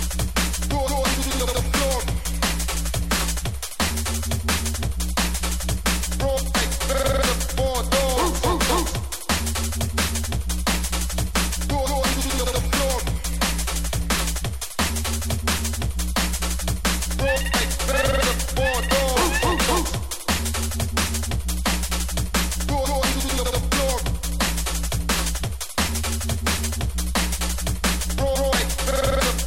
TOP >Vinyl >Drum & Bass / Jungle
TOP > Vocal Track
TOP > Jump Up / Drum Step